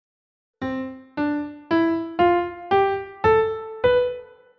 На этой странице собраны звуки нот в разных тембрах: от классического фортепиано до электронного терменвокса.
Звуки музыки: ноты До Ре Ми Фа Соль Ля Си